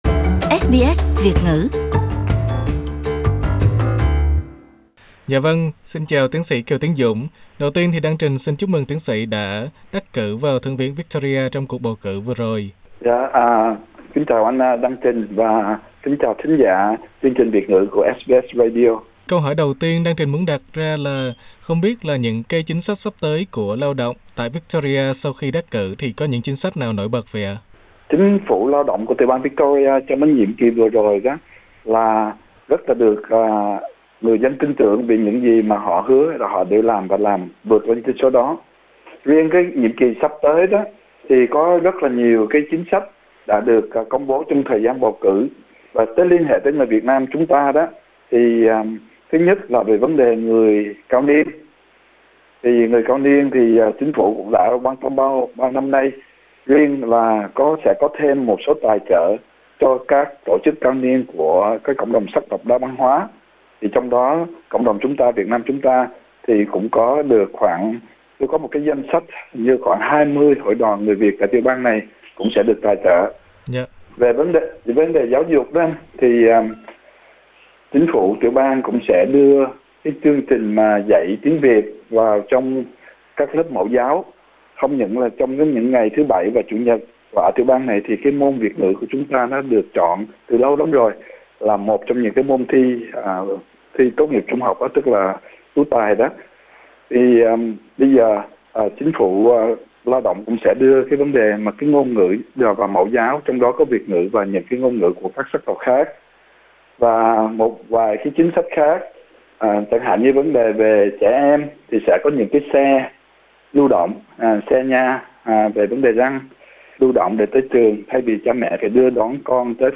Nhân dịp Tiến sĩ Kiều Tiến Dũng đắc cử vào Thượng viện Victoria, SBS Việt ngữ đã có một cuộc phỏng vấn ngắn xoay quanh các chủ đề dạy tiếng Việt trong trường mầm non, Safe Schools, xây dựng hạ tầng cơ sở và các chính sách khác của Lao động tại tiểu bang này.